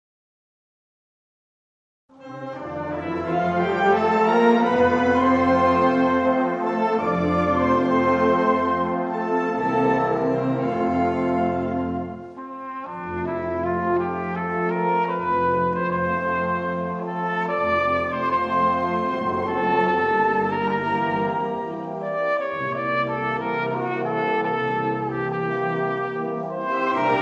Eine ruhig dahin fließende Melodie für Solo Flügelhorn
Gattung: Solo-Flügelhorn
Besetzung: Blasorchester